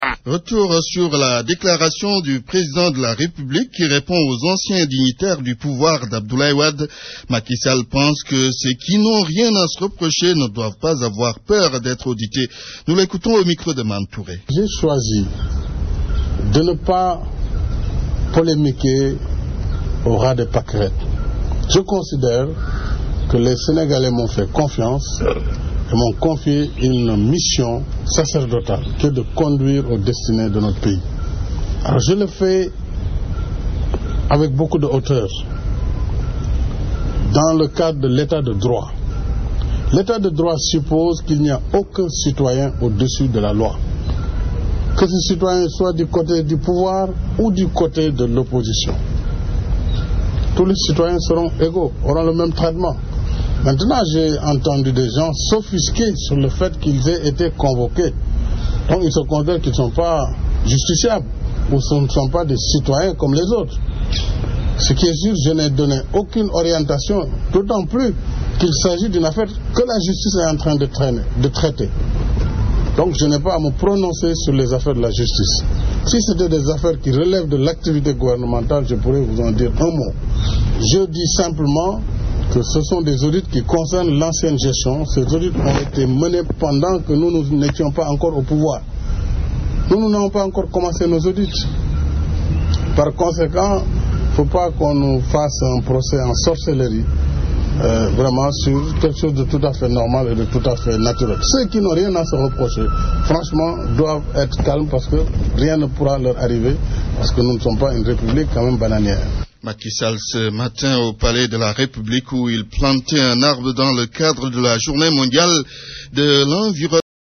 Macky Sall qui plantait un arbre ce matin au palais dans le cadre de la journée mondiale de l’environnement s’est refusé de polémiquer au « ras de pâquerettes ». Le chef de l’Etat a rappelé que les sénégalais l’ont élu et attendent de lui qu’il réponde à leurs attentes.